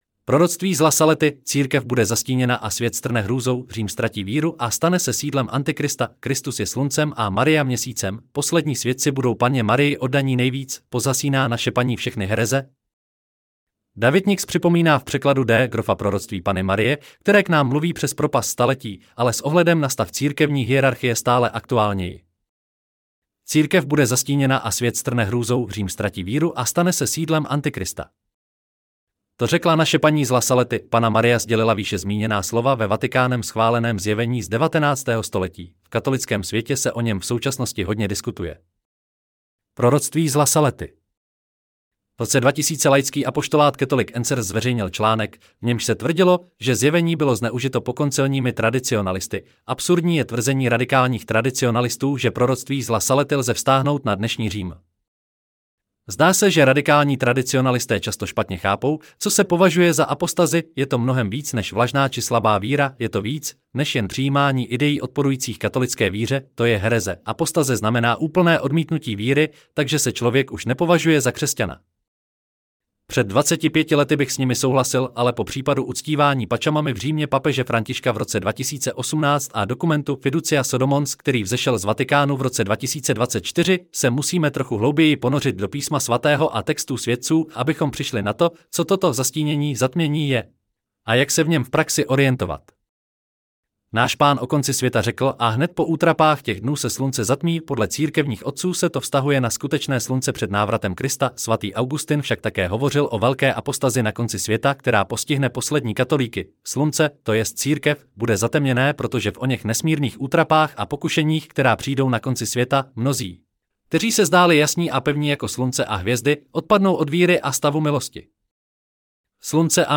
Proroctví z La Saletty: Celý článek si můžete poslechnout v audioverzi zde: Untitled 19.12.2024 Proroctví z La Saletty: Církev bude zastíněna a svět strne hrůzou.